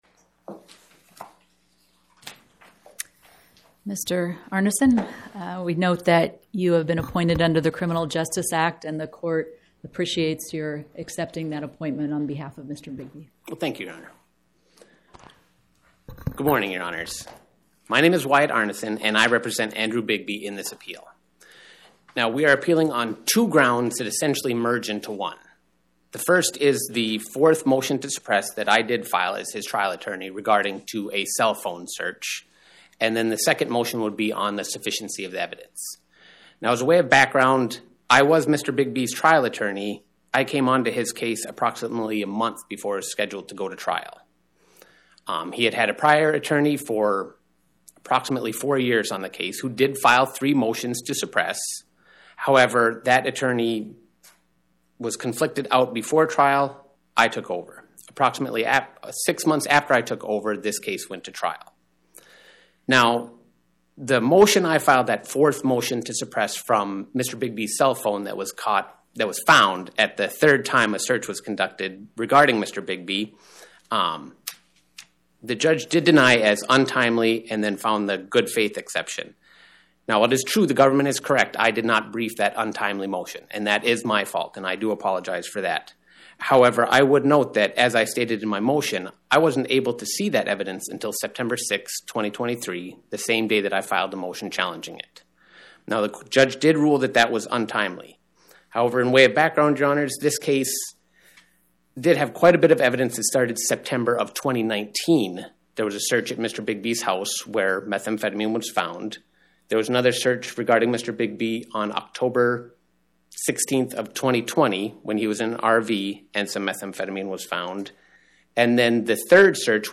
Oral argument argued before the Eighth Circuit U.S. Court of Appeals on or about 10/21/2025